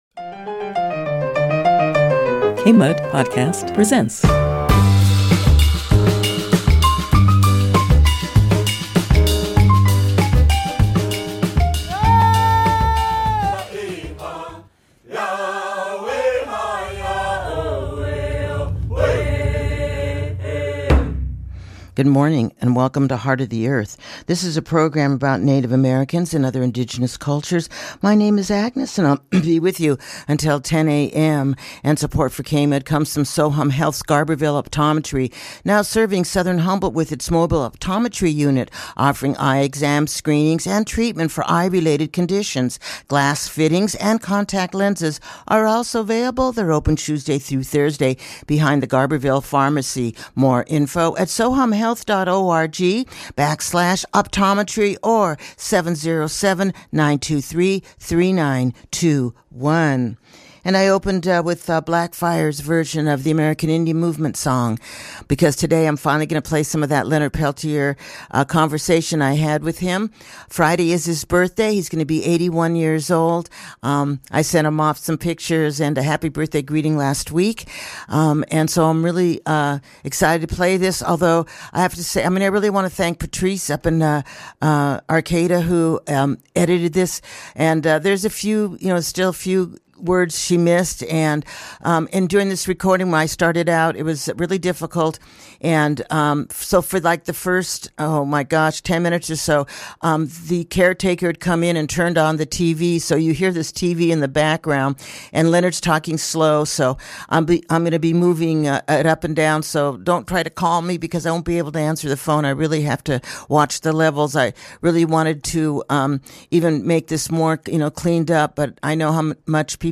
A conversation with Leonard Peltier about his life, the FBI and freedom.